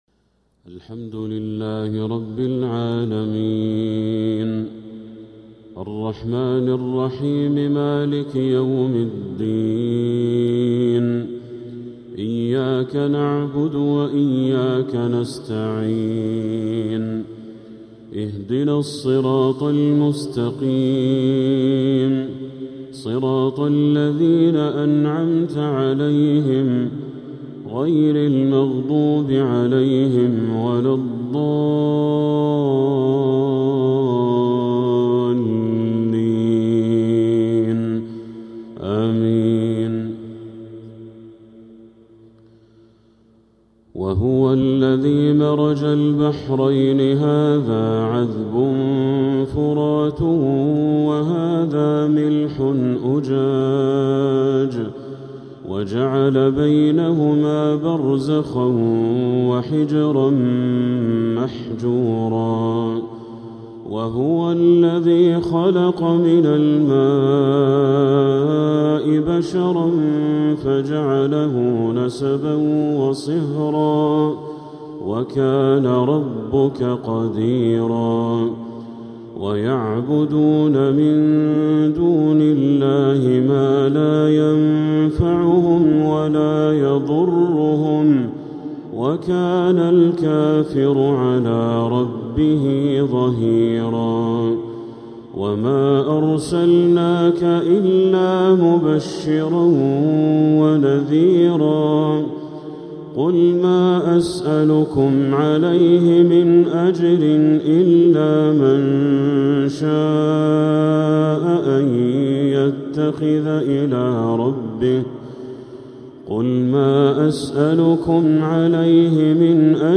مغرب الأحد 11 محرم 1447هـ | من سورة الفرقان 53-59 | Maghrib prayer from Surat al-Furqan 6-7-2025 🎙 > 1447 🕋 > الفروض - تلاوات الحرمين